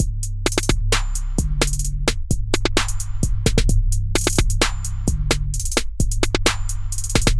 陷阱鼓
Tag: 130 bpm Trap Loops Drum Loops 1.25 MB wav Key : Unknown